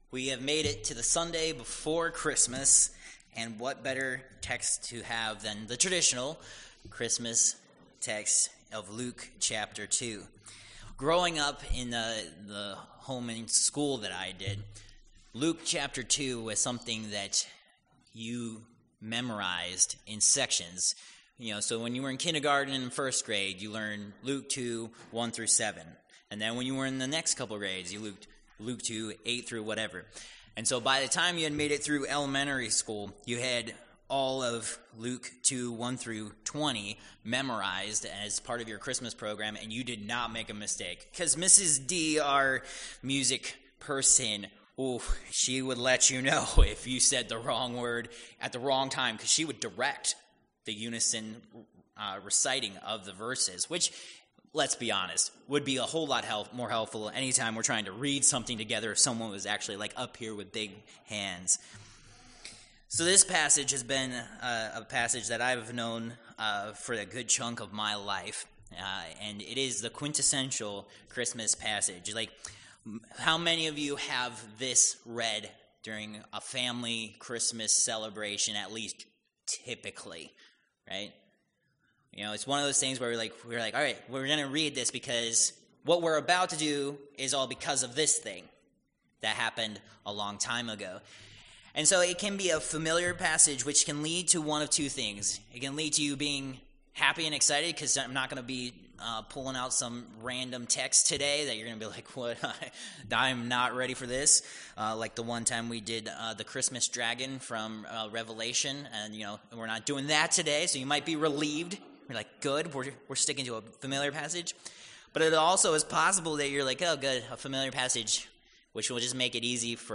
Passage: Luke 2:1-20 Service Type: Worship Service